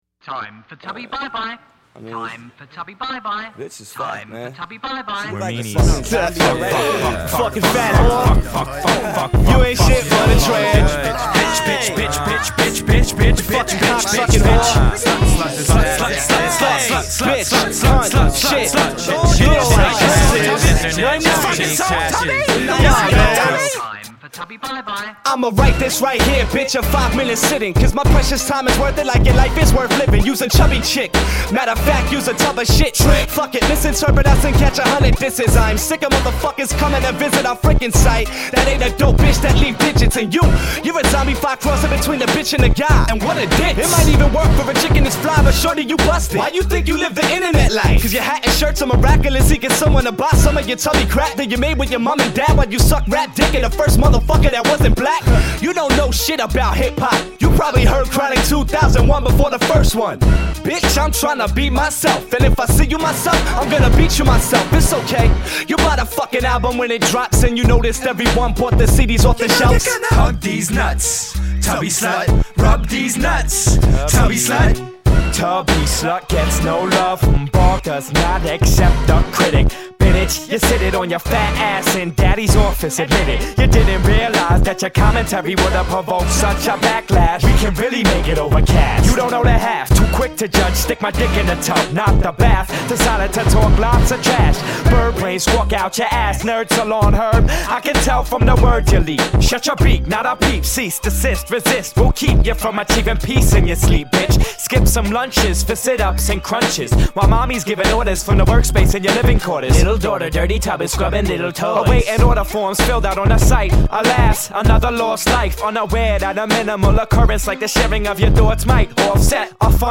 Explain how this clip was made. Roughly recorded and roughly mixed at Delco Plaza